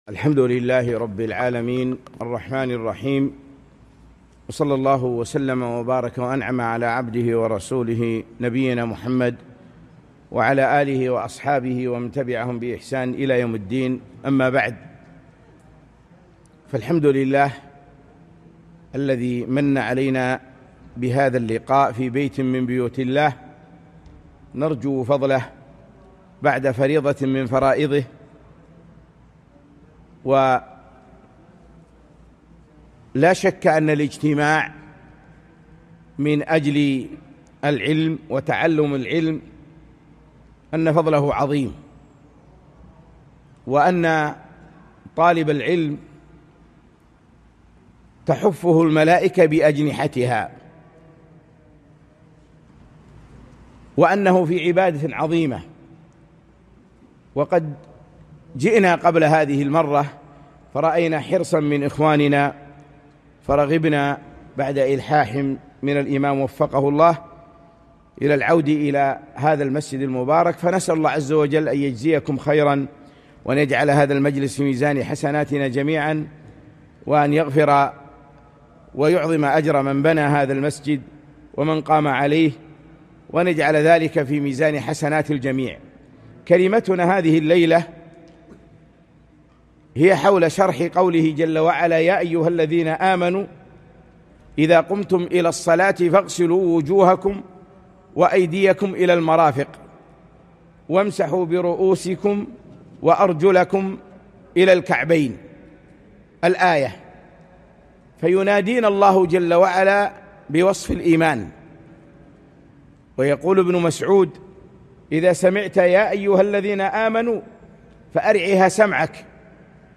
محاضرة - (يا أَيُّهَا الَّذينَ آمَنوا إِذا قُمتُم إِلَى الصَّلاةِ فَاغسِلوا وُجوهَكُم﴾ - دروس الكويت